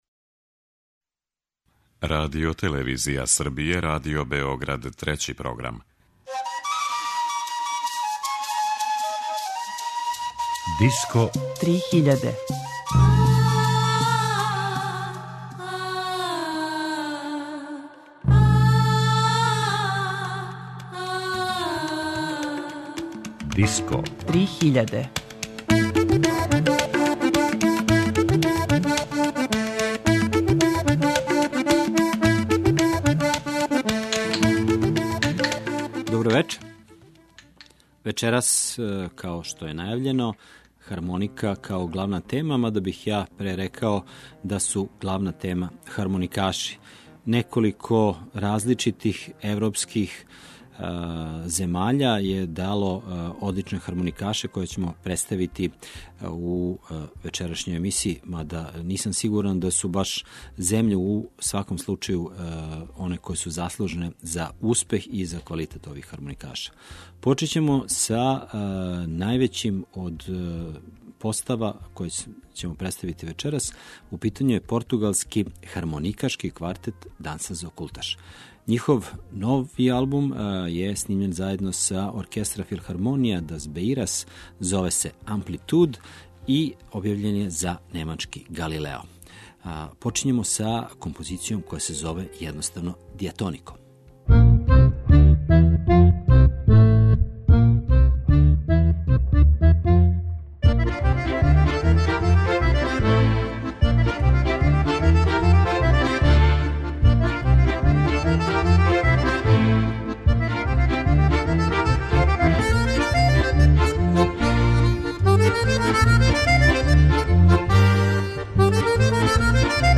Вечерашња емисија је посвећена хармоници, инструменту веома заступљеном у светској музици, на свим континентима.